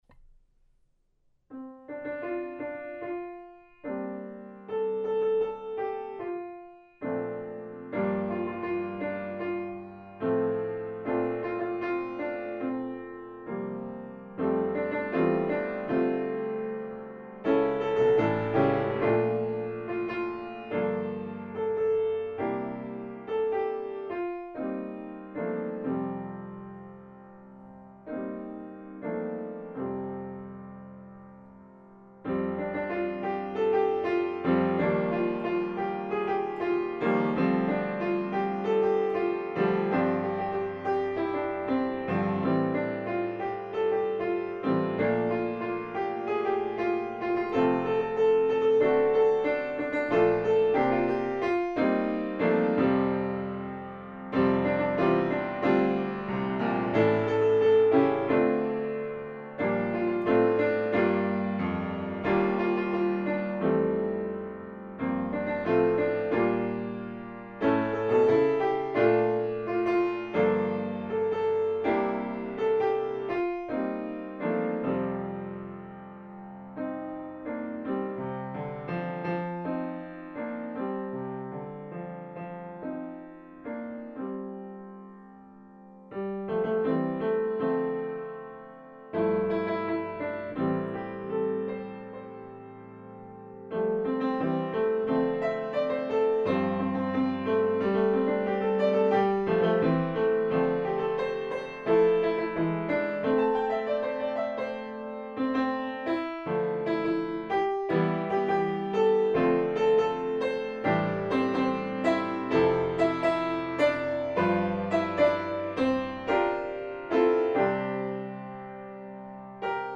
I’m pretty sure I haven’t adequately rocked out anything with my version, but working on this was a good reminder of how some songs with such sheer simplicity can catch the attention of a wide range of us – the young, the old, the rockers and the piano players.
There is a sense that it is an old Negro Spiritual, and it does have ties with the Civil Rights Movement in the 1950s and 1960s, but it was originally written as a children’s gospel song by Harry Dixon Loes around 1920.